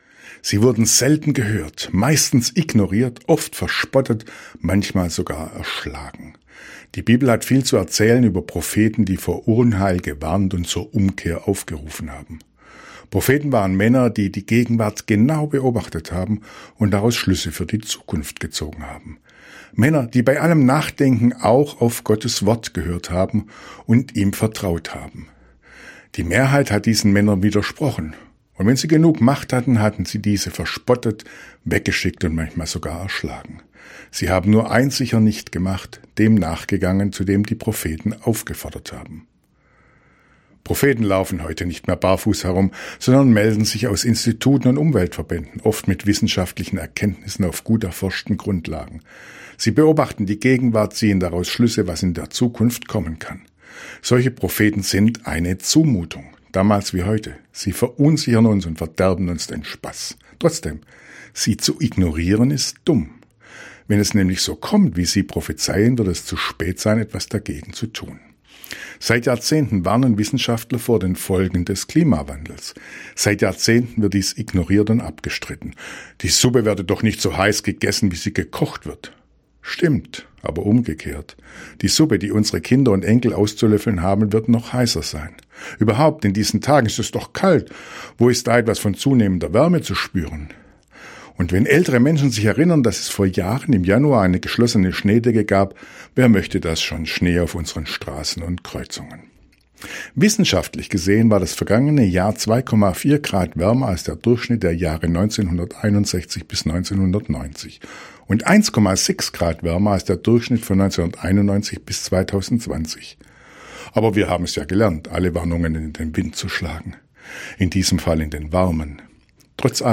Radioandacht vom 8. Januar – radio aktiv